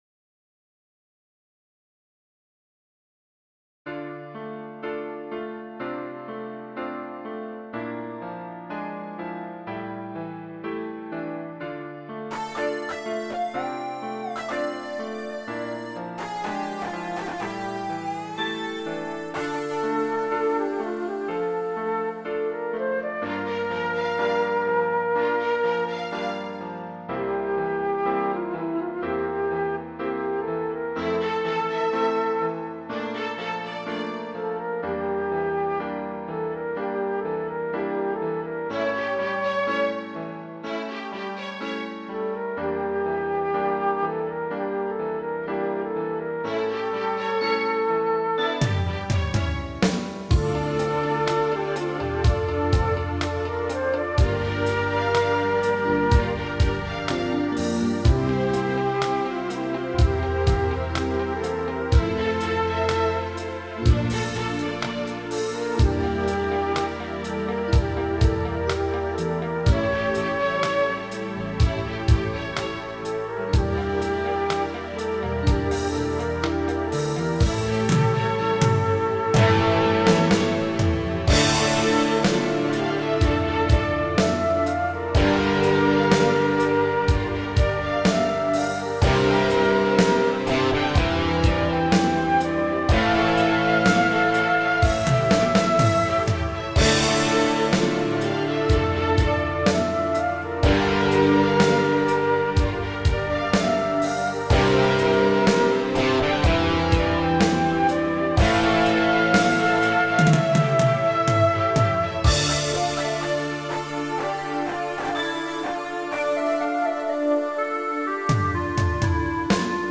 Мелодия песни